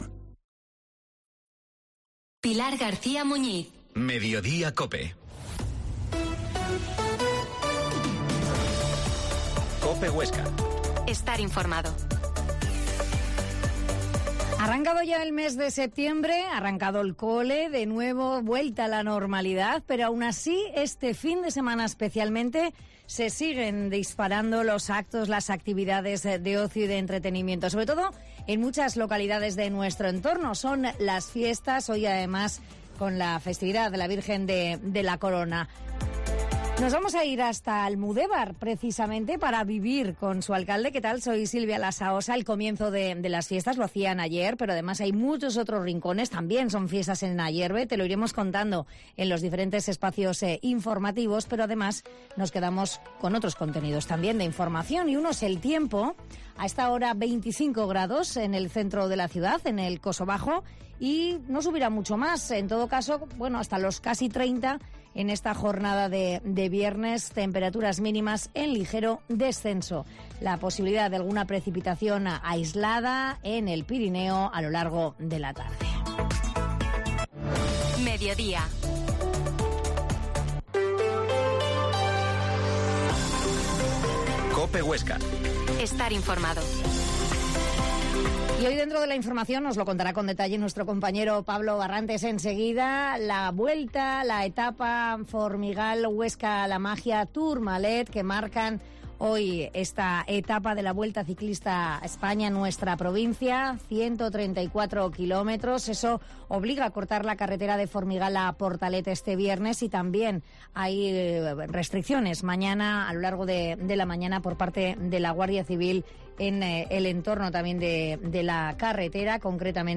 Mediodia en COPE Huesca 13.20 Entrevista al alcalde de Almudevar, José Luis Abad